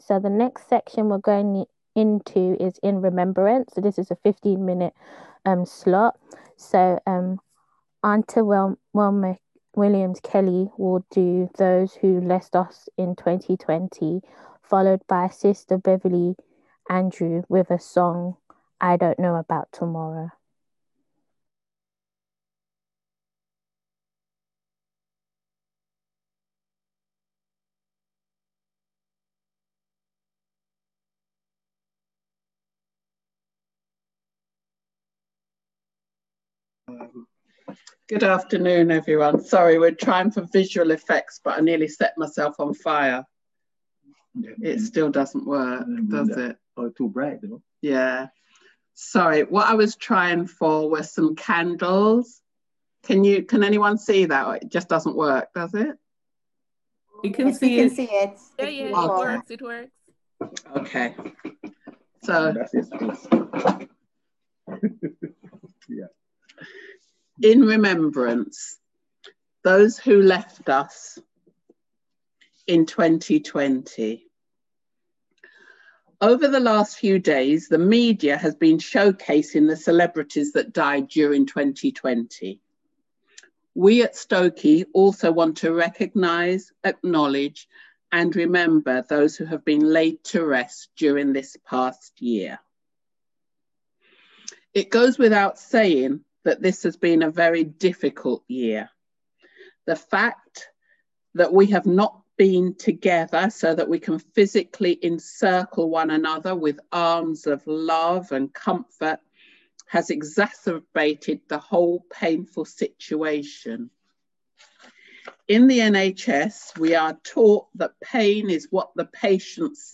on 2021-01-01 - End of Year Service 31.12.20